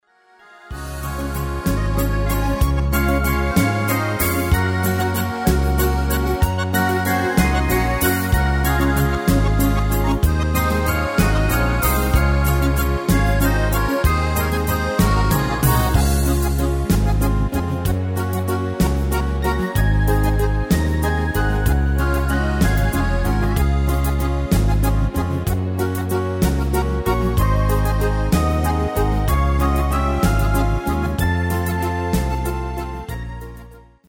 Extended MIDI File Euro 12.00